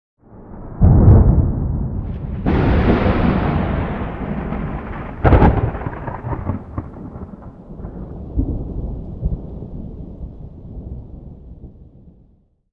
storm_3.ogg